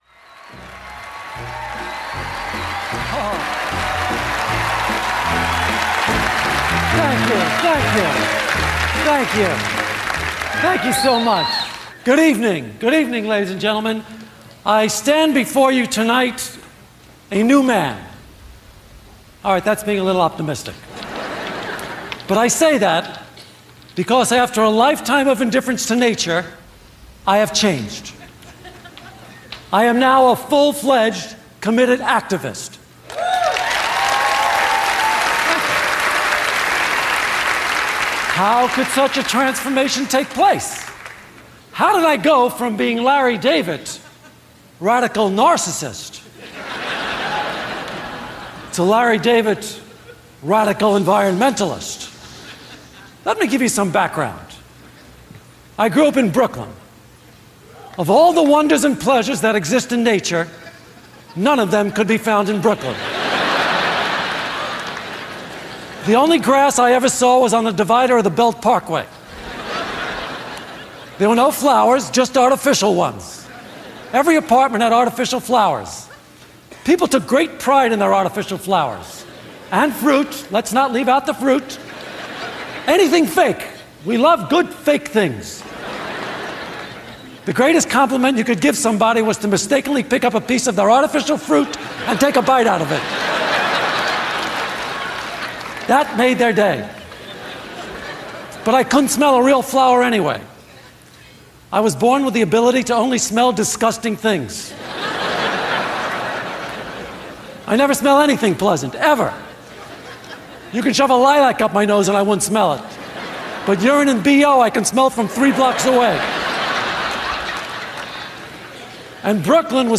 larry-david-earth-to-the-environment.mp3